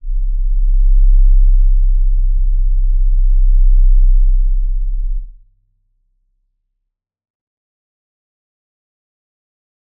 G_Crystal-C1-f.wav